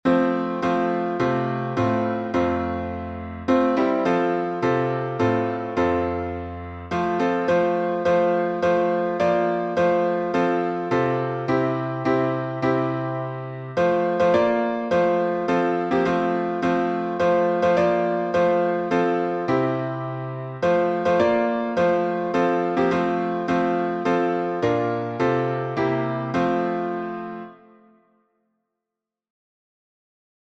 Revive Us Again — F major.